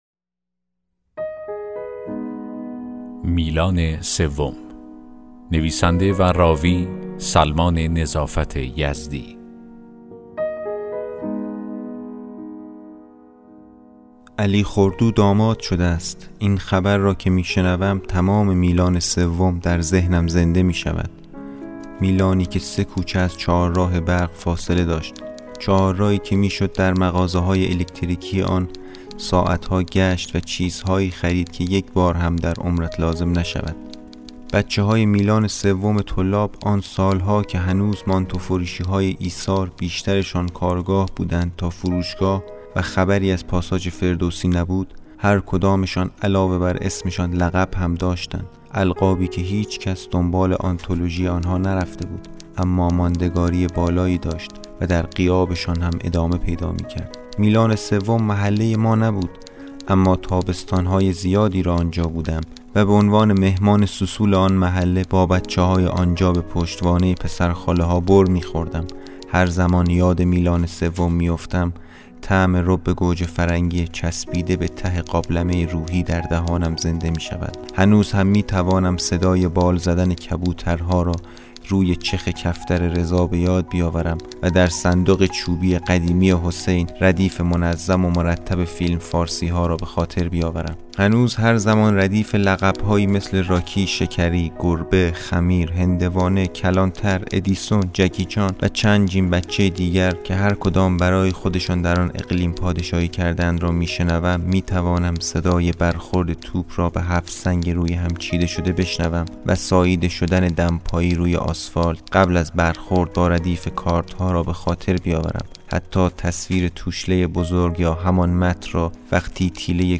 داستان صوتی: بچه‌های میلانِ سوم